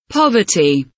poverty kelimesinin anlamı, resimli anlatımı ve sesli okunuşu